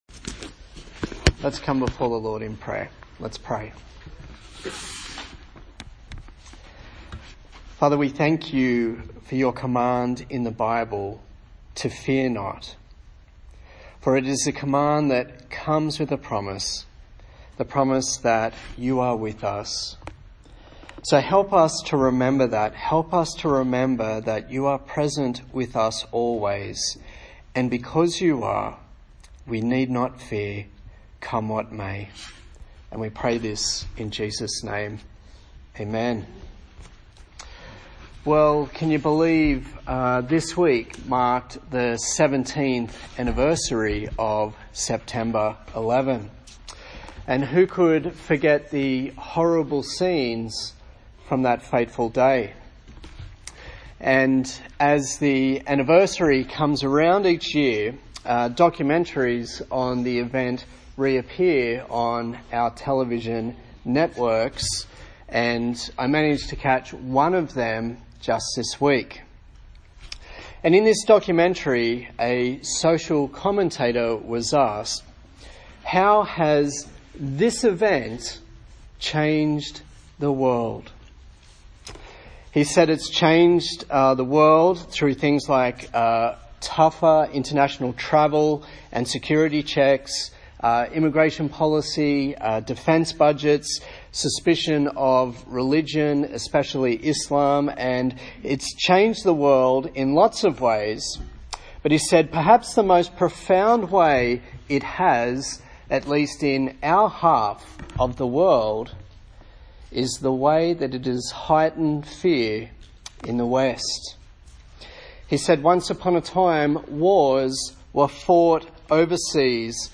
A sermon in the series on the book of Isaiah